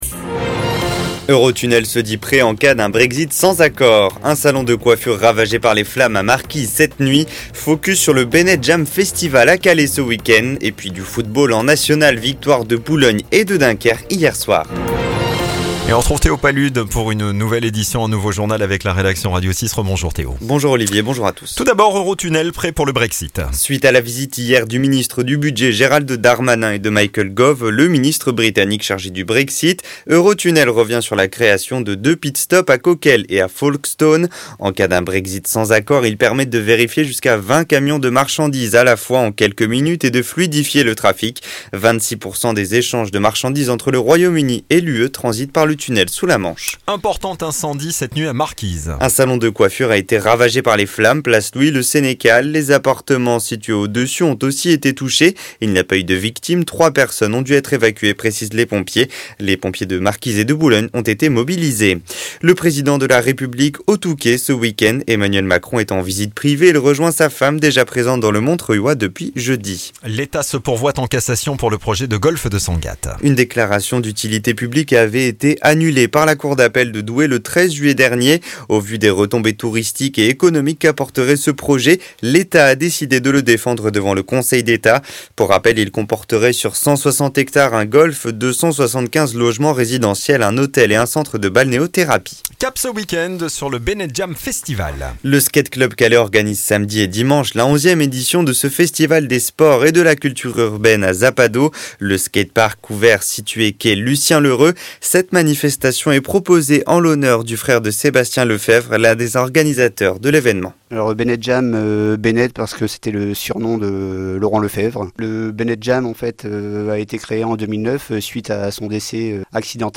Le journal Cote d'Opale du samedi 31 août
Retrouvez l'essentiel de l'actualité de la Côte d'Opale, la Côte Picarde et les grands titres des infos en France et dans le monde en 6 minutes. Journal de 9h.